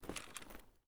sounds / weapons / rattle / lower